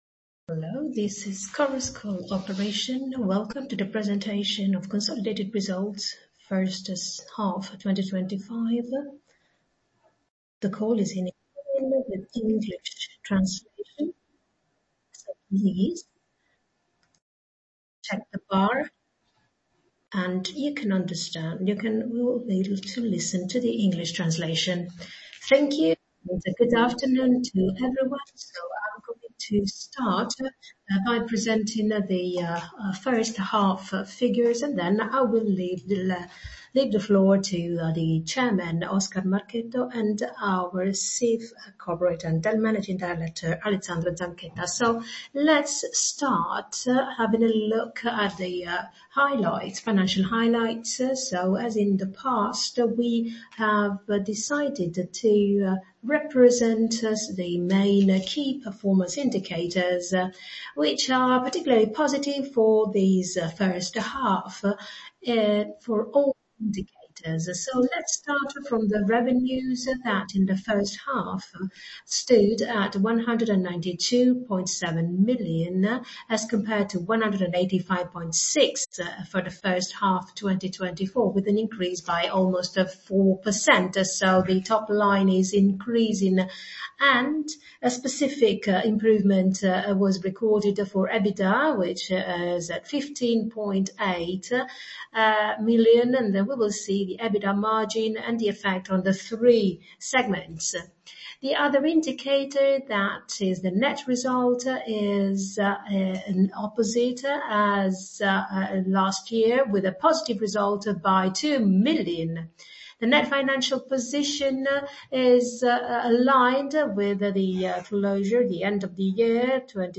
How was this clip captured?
1H 2025 Results presentation conference call (audio)